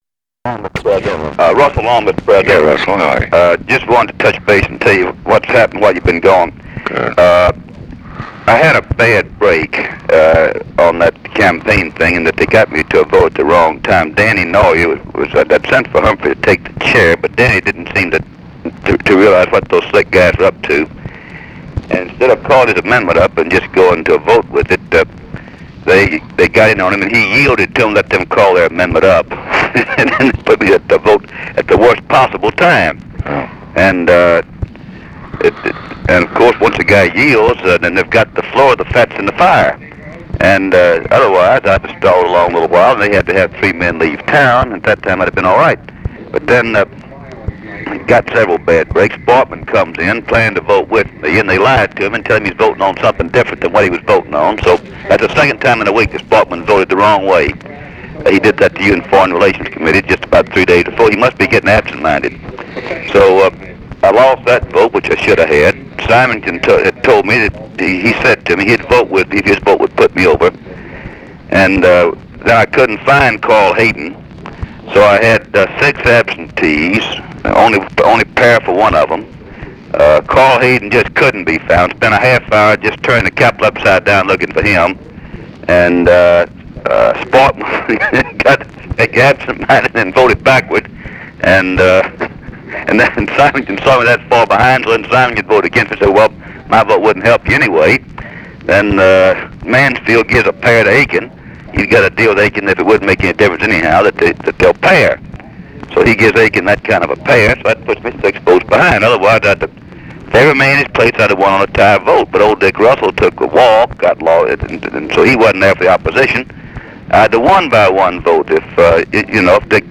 Conversation with RUSSELL LONG, April 19, 1967
Secret White House Tapes